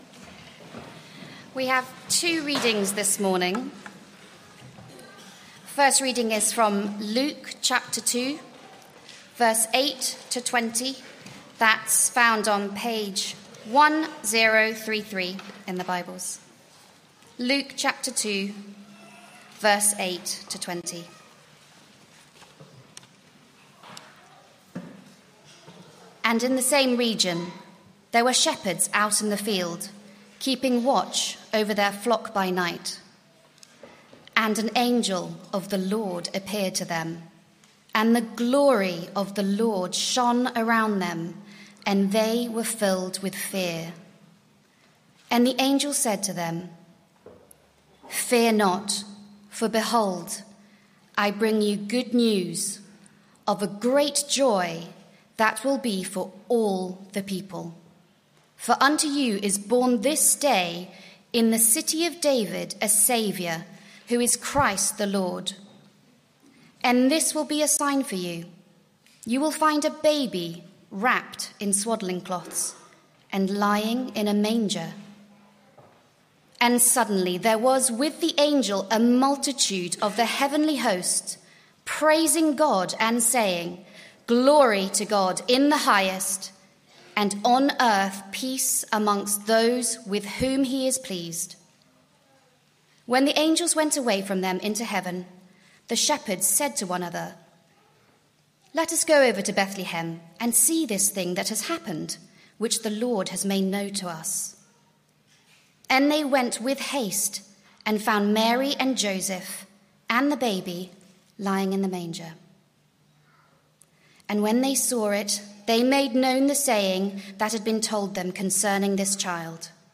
Why did Jesus come? Sermon - Audio Only Search media library...